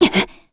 Valance-Vox_Landing_kr.wav